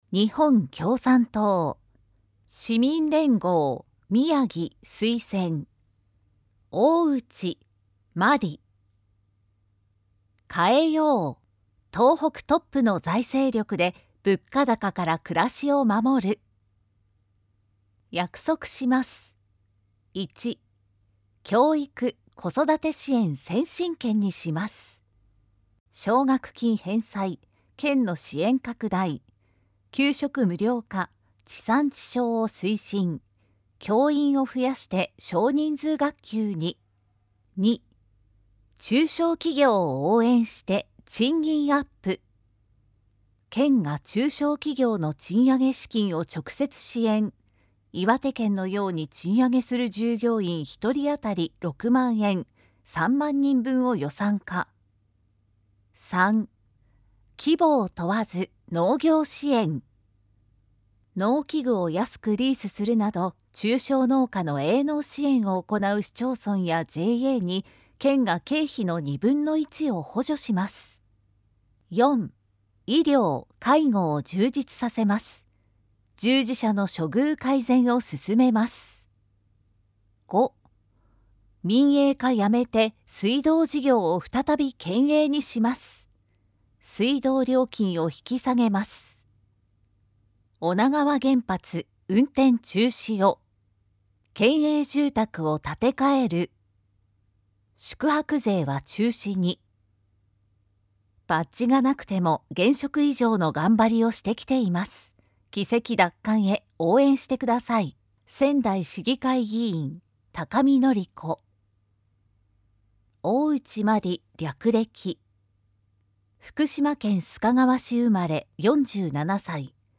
宮城県議会議員補欠選挙（宮城野選挙区）候補者情報（選挙公報）（音声読み上げ用）
選挙公報音声版（MP3：2,101KB）